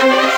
50 Cent String Hit.wav